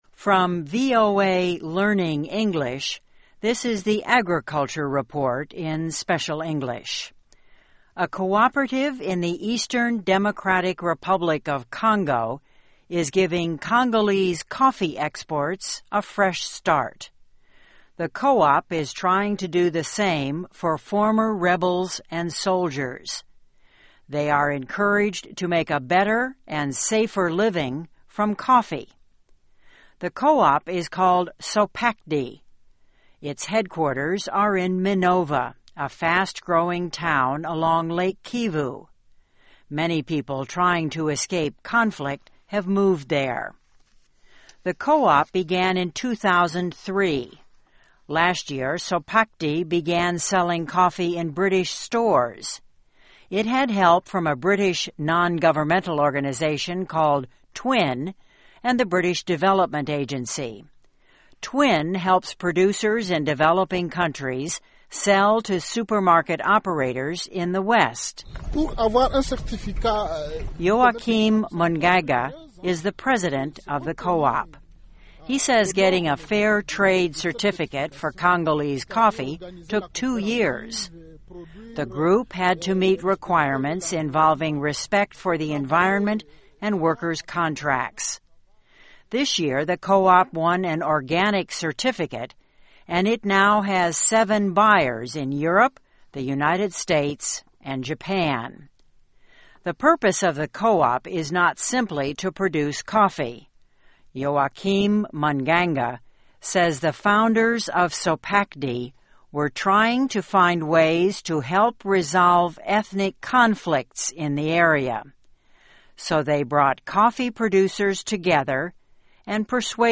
您现在的位置：VOA慢速英语 > 农业报道 > 刚果民主共和国东部前武装分子从咖啡中获得新生